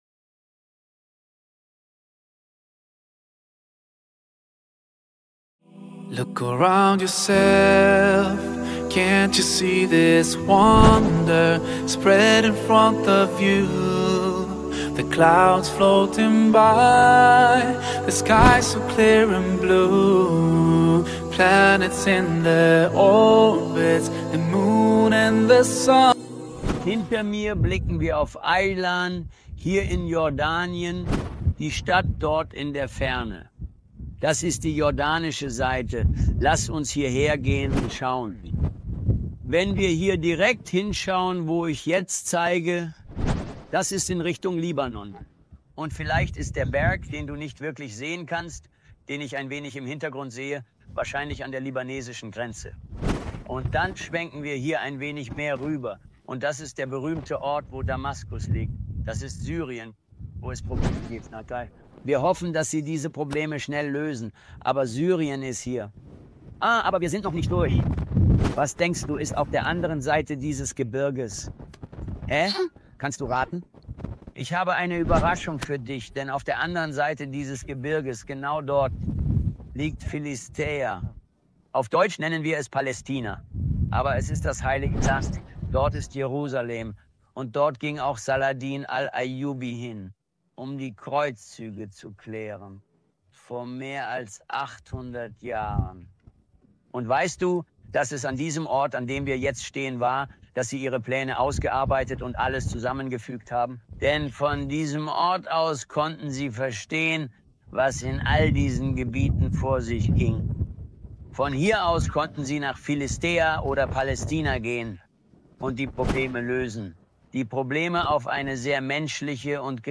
filmed in the backdrop of scenic landscapes and historic places of Jordan.